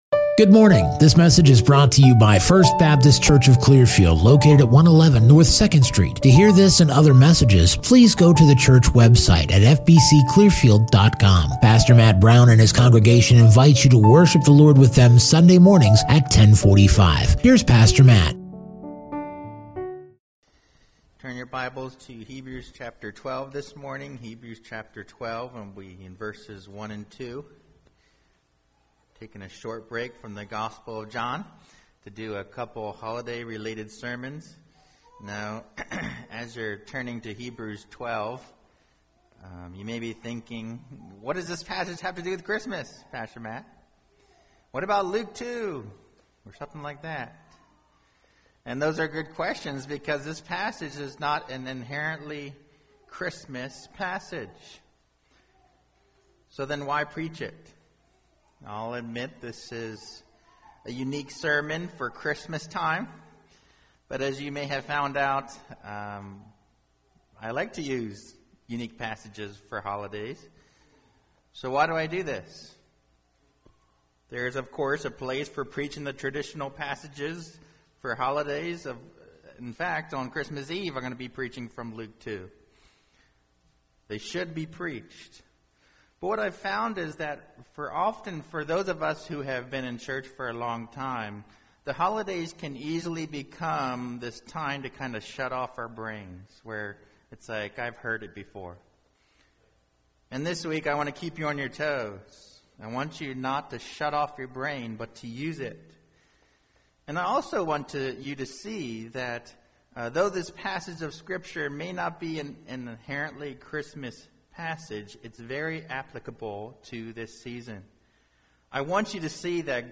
Non-Series Sermon Passage: Hebrews 12:1-2 Bible Text